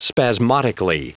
Prononciation du mot : spasmodically
spasmodically.wav